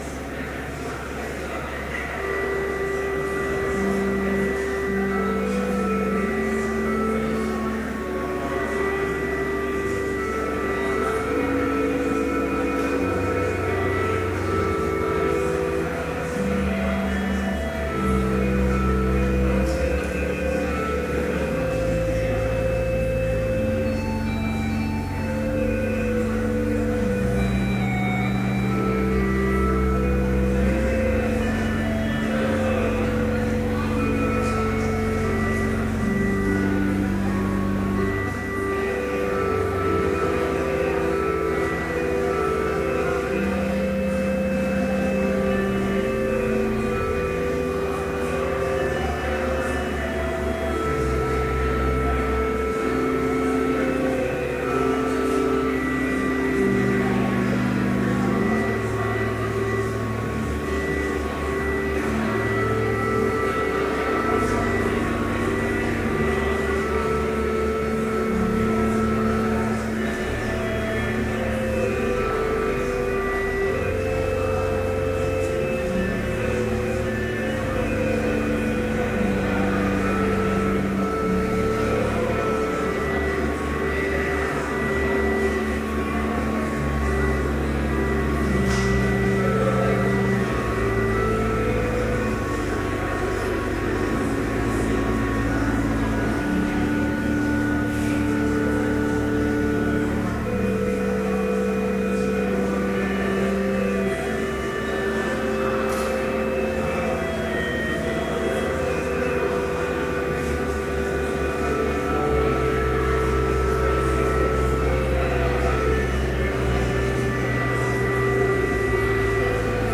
Complete service audio for Chapel - October 7, 2013